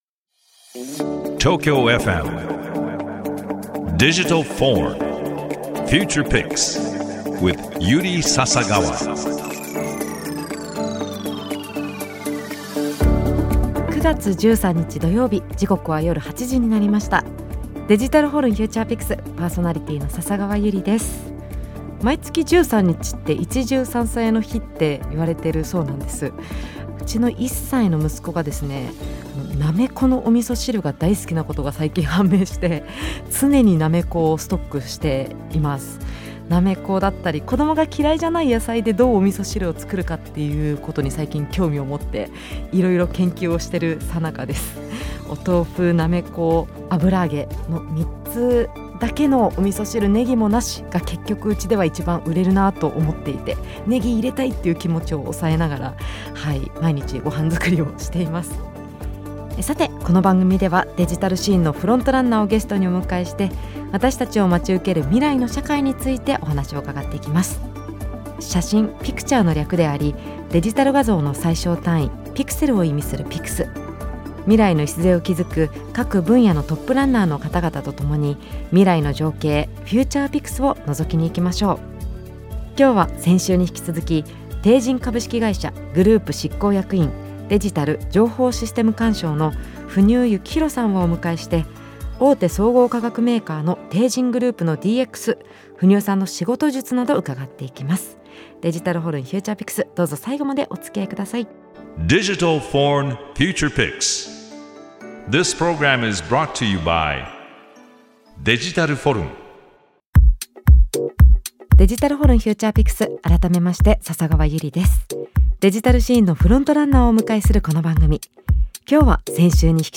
この番組では、デジタルシーンのフロントランナーをゲストにお迎えして、私達を待ち受ける未来の社会についてお話を伺っていきます。